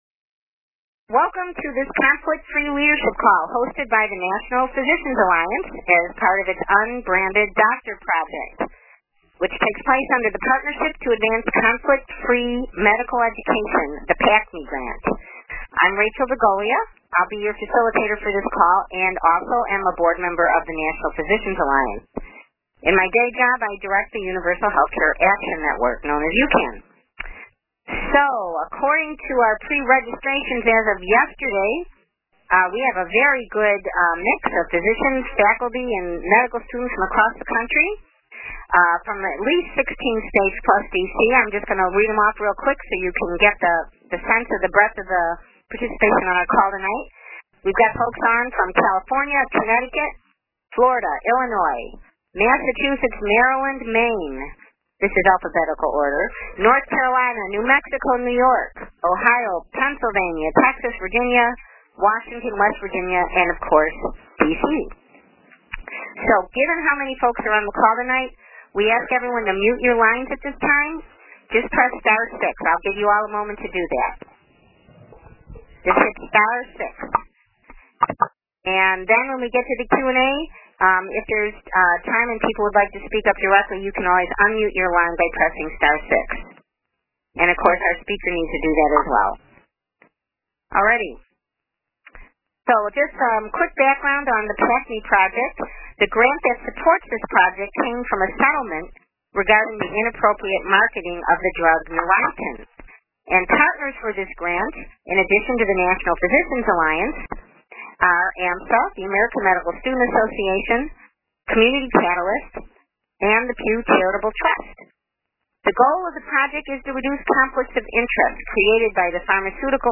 This lecture series highlights advocacy and policy experts who provide technical assistance and leadership development for physicians, residents, and medical students interested in conflict-of-interest reform efforts. The lecture was aired live on Wednesday, October 1, 2014.